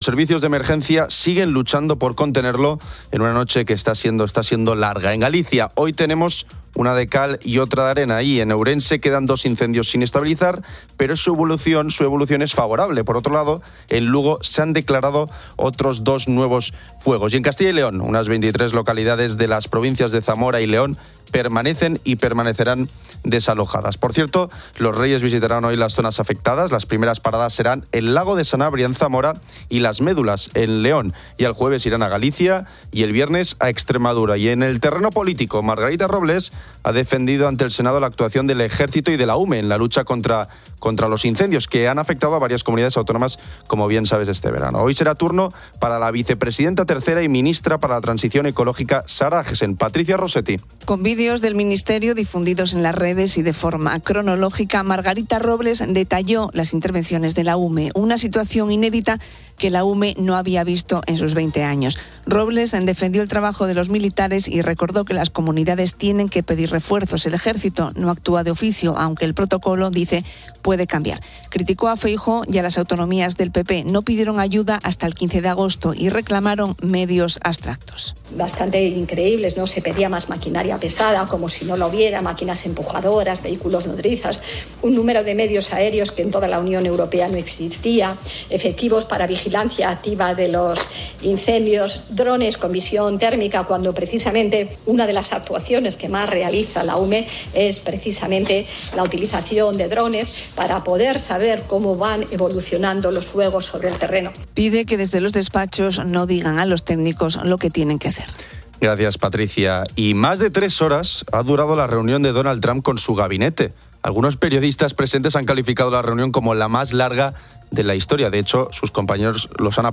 Una sección extensa se dedica a la repostería española, destacando dulces como la Trenza de Almudévar, los Miguelitos de La Roda, las Corbatas de Unquera, los Sobaos Pasiegos, el Mazapán de Soto, los Pasteles Rusos de Alfaro, los Feos de Villalpando, los Almendrados de Allariz, las Tortas de Alcázar de San Juan y los Carajitos del Profesor. El programa finaliza con una entrevista